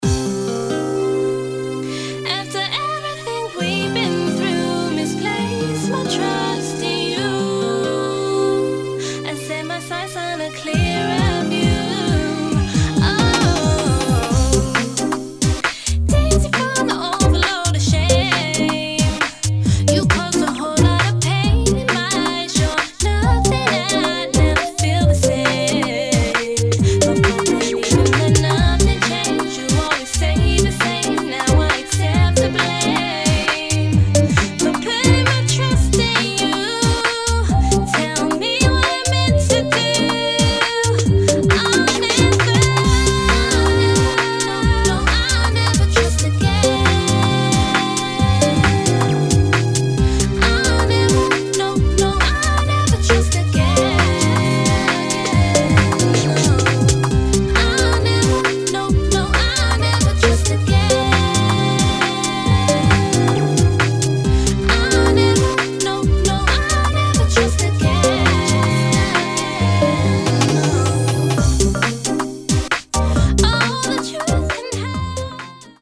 [ UK GARAGE ]